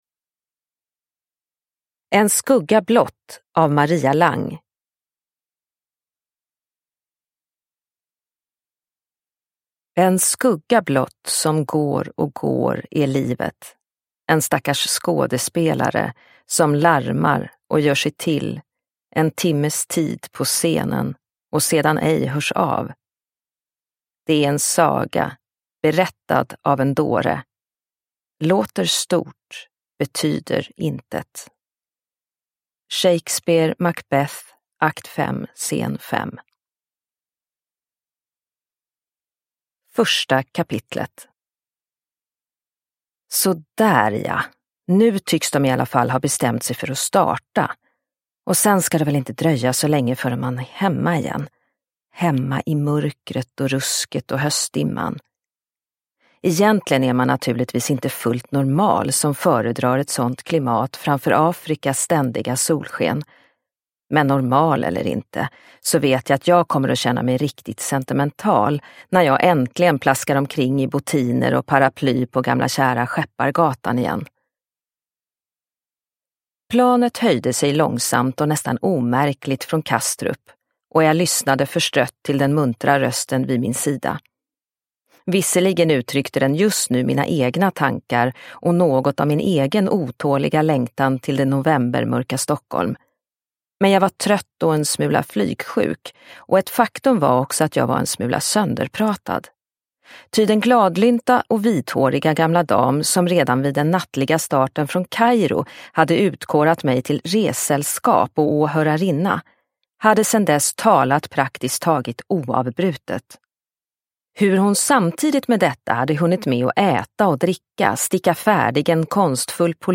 En skugga blott – Ljudbok – Laddas ner